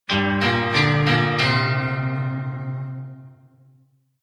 Звуки проигрыша
На этой странице собрана коллекция звуков проигрыша и «Game Over» из различных игр.
Game lost